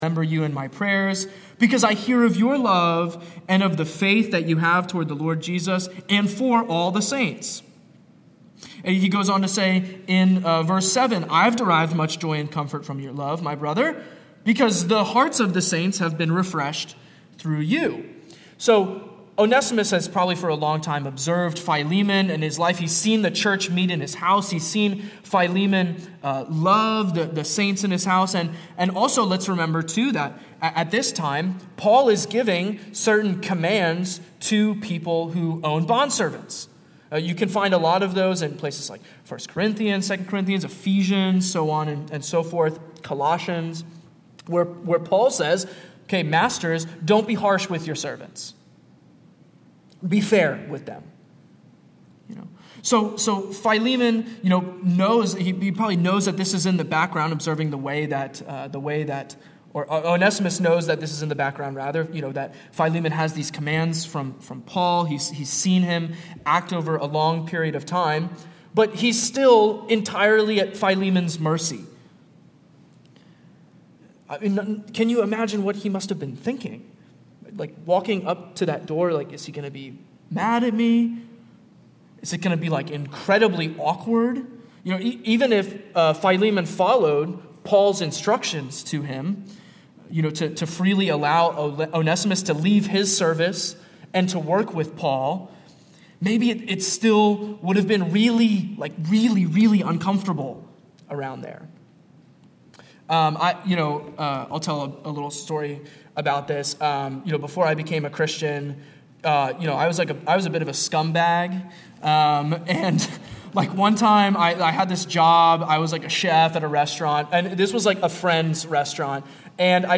Sermon-97-1.mp3